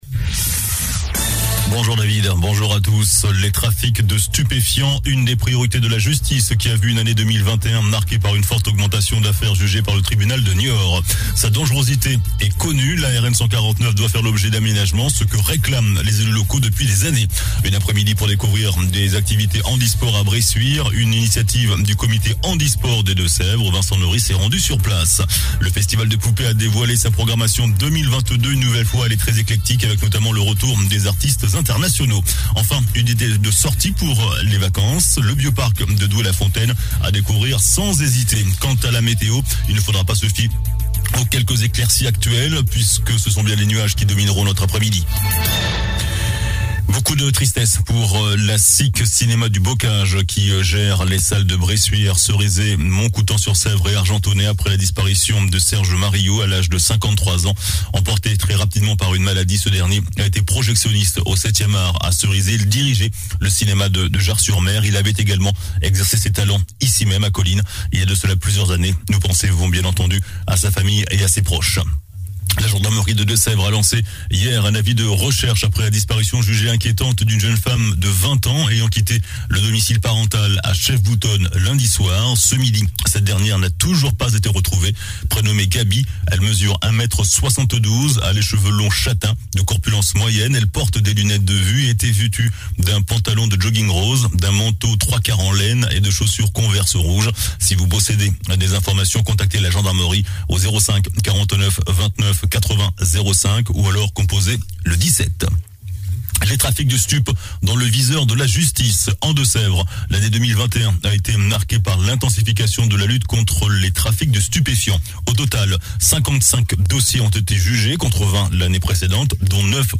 JOURNAL DU LUNDI 24 JANVIER ( MIDI )